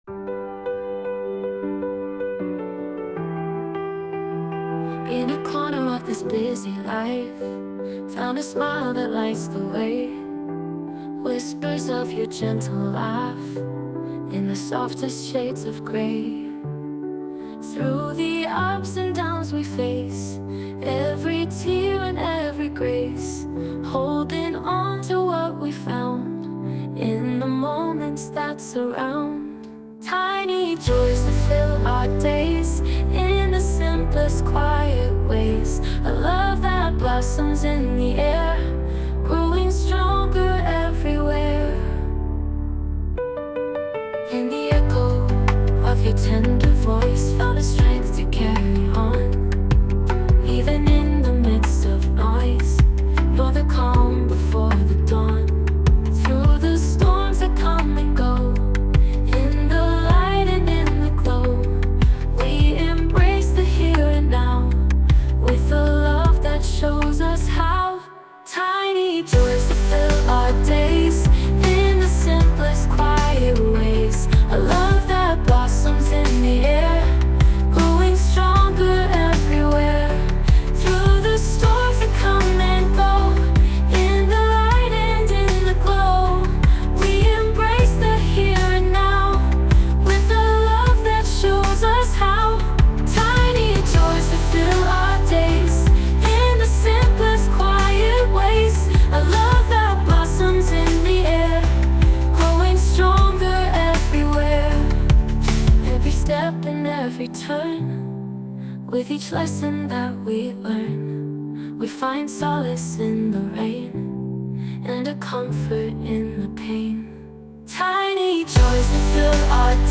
著作権フリーBGMです。
女性ボーカル（洋楽・英語）曲です。
穏やかな調子で、生活の中の小さな瞬間の大切さが伝わったらいいなと思います。